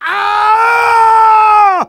Voice file from Team Fortress 2 Russian version.
Spy_paincrticialdeath02_ru.wav